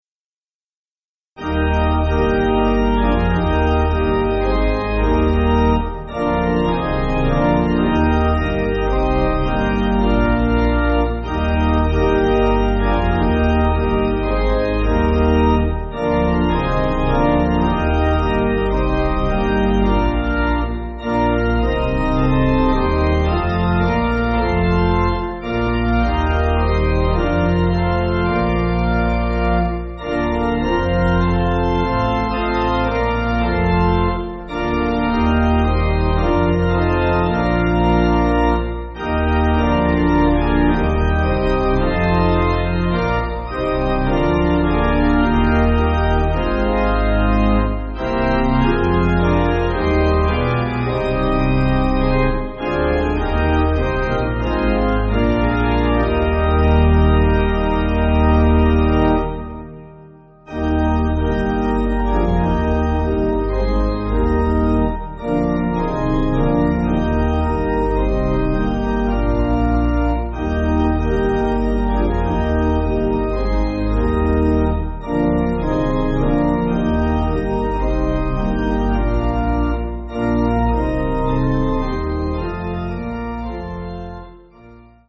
Organ
(CM)   4/Eb